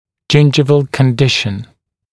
[‘ʤɪnʤɪvəl kən’dɪʃ(ə)n] [ʤɪn’ʤaɪvəl][‘джиндживэл кэн’диш(э)н] [джин’джайвэл]состояние десны